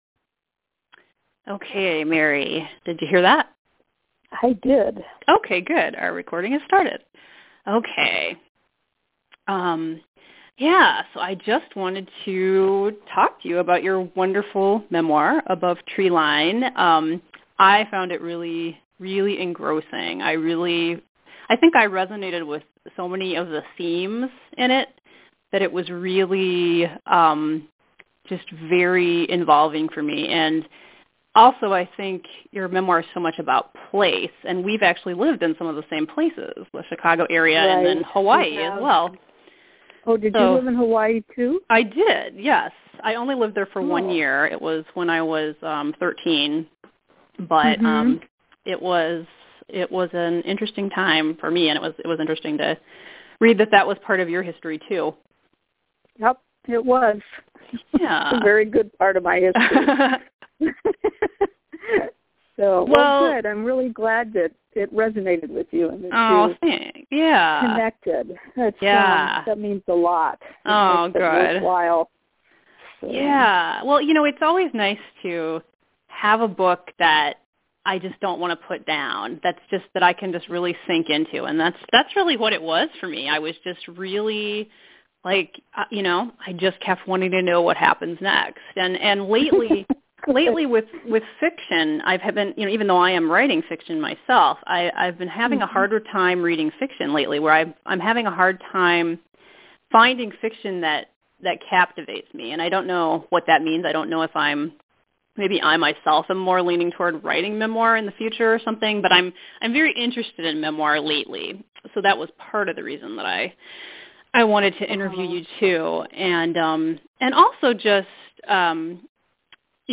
A conversation with memoirist